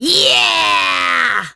penny_lead_vo_03.wav